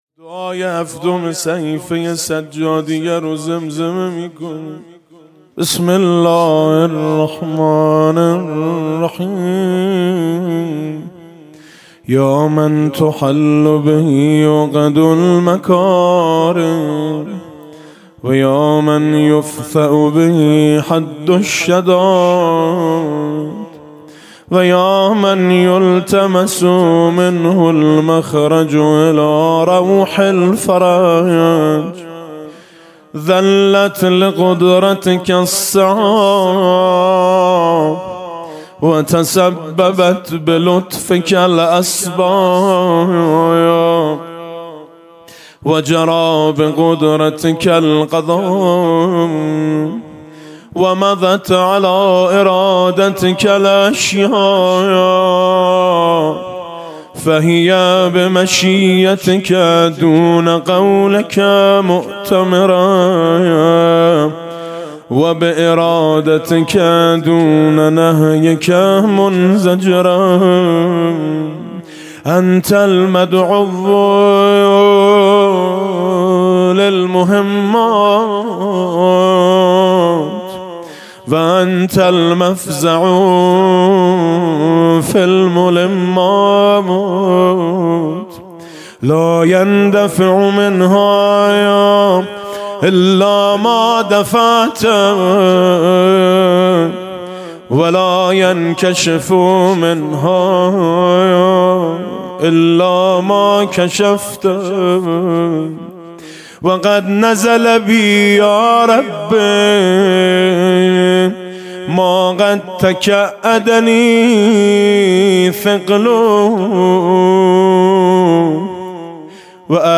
صوت/ دعای هفتم صحیفه سجادیه با نوای مطیعی